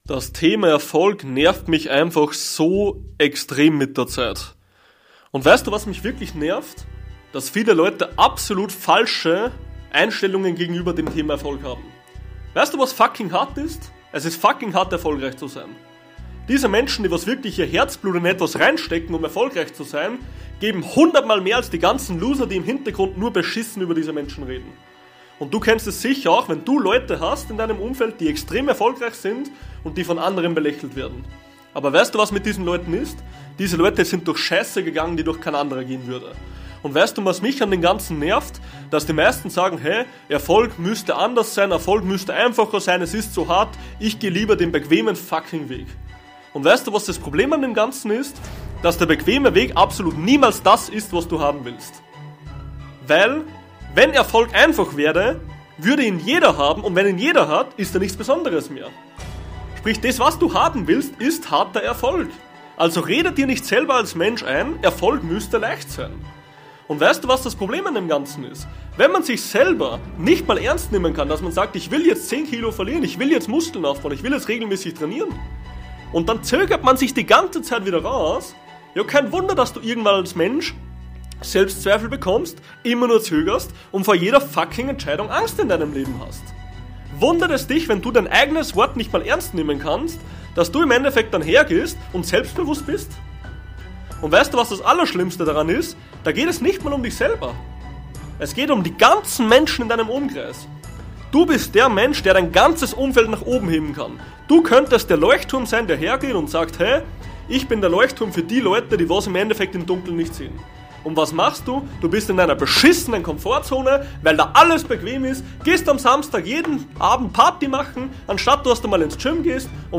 Brich den Komfort (Motivations-Ansage)